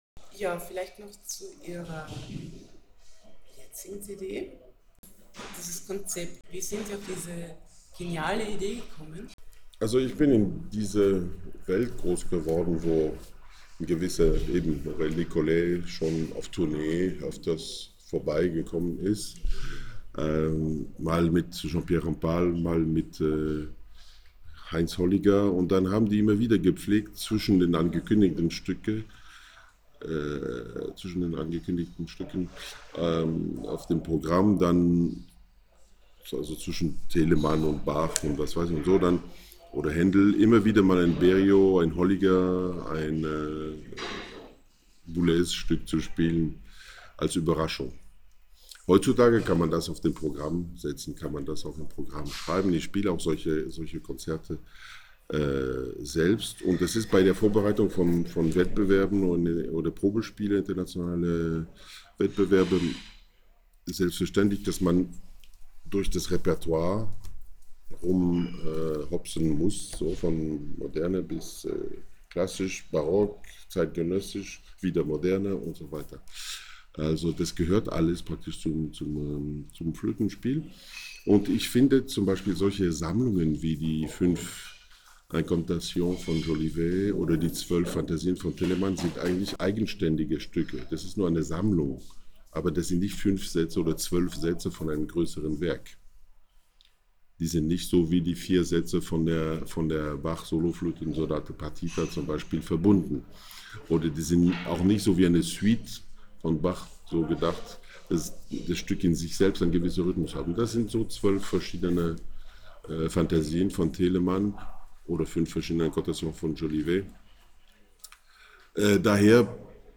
Er hat sich eine ganze Stunde Zeit genommen und in der Kantine der Berliner Philharmonie meine Fragen beantwortet. Hier erzählt er über seine neue CD „Solo“: Zeitgenössisches und Telemann Fantasien.
Pahud-Interview-NEUe-CD.wav